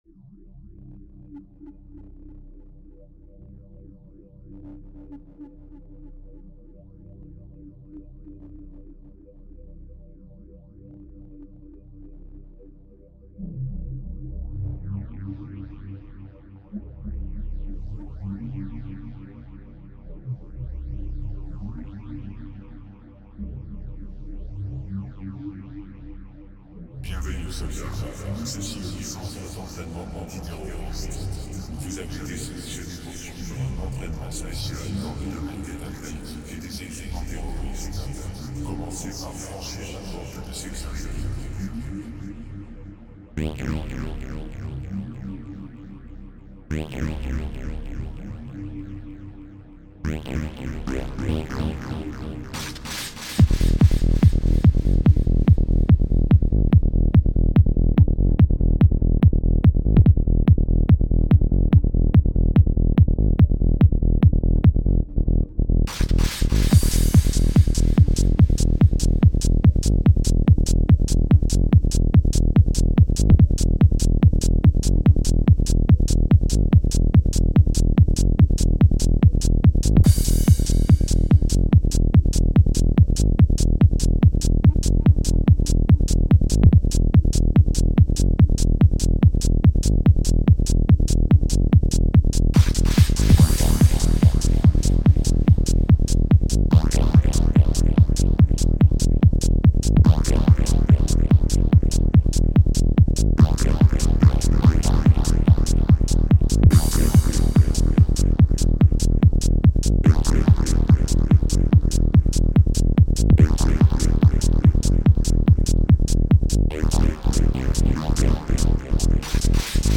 Mon petit track full on
Salut a tous les gens voila un des premier track que j'ai fait bon il est assez repetitif c'est vrai bon soyez indulgent c'est dans mes premier track ceux que je fais maintenant sont un peu plus nourris de son psyché Good vide a tous
sympa ton tack...juste 2/3 truc qui me choc :la rytmique est faiblarde en velocité et dynamique..le charley est trop loin et tout le temps ouvert...le snare pareil...pas assez fort ce qui fait qu'on le croit pas en place ou un peu decalé...remonte le et decale le un peu  qu'il claque plus avec le kik....parlons du kik...plutot bien mais trop compressé je trouve...et toujours tout droit...fais des variantes pour les reprises ou les breaks..ca c'etait le commentaire du casse couille...sinon pour le mec sympa : plutot bonne melodie,des bonnes idées..allez des efforts, un peu + de fx,de dynamiques,quelques breaks un peu + chiadé et t'es pas loin d'la bonne full on sympa....a+